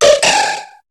Cri de Manzaï dans Pokémon HOME.